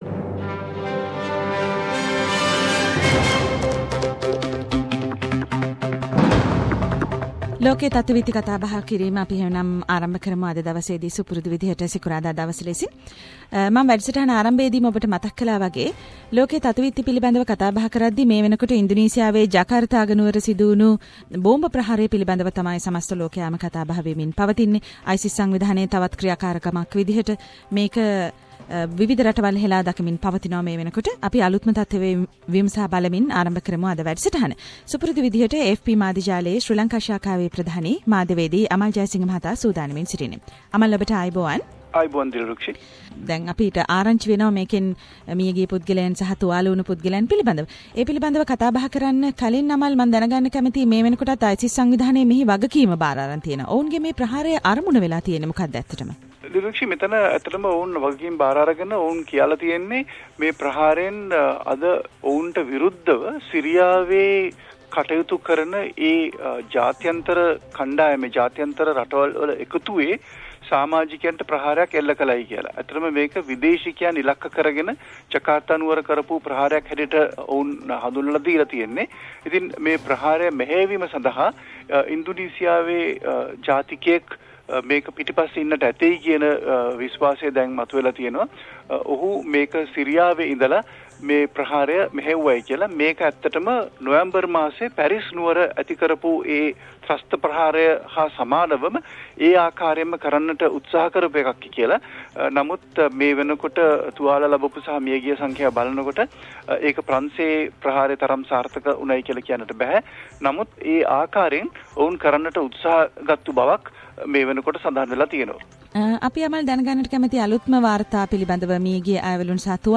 SBS Sinhala Around the World - Weekly World News highlights…..